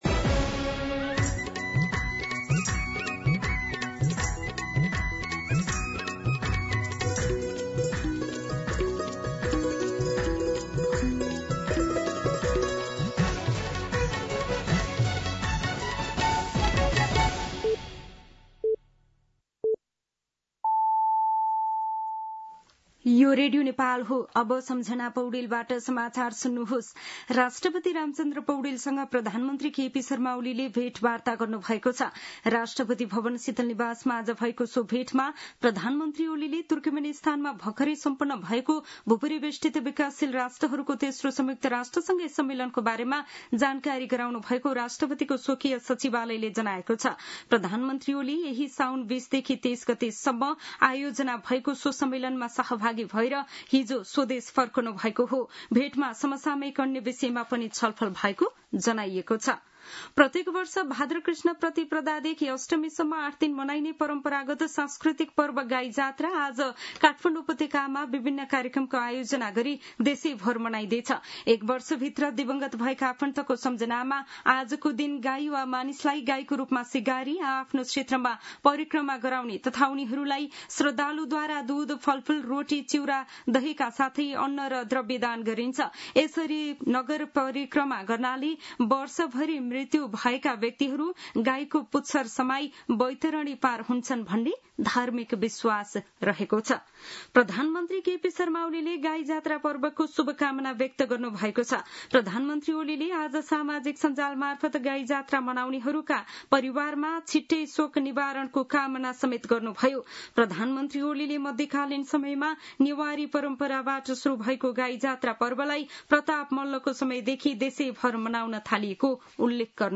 दिउँसो १ बजेको नेपाली समाचार : २५ साउन , २०८२
1-pm-Nepali-News-3.mp3